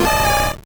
Cri de Machopeur dans Pokémon Or et Argent.